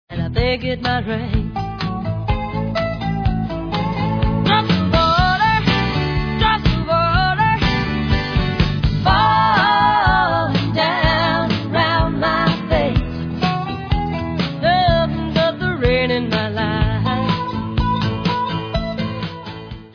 sledovat novinky v kategorii Country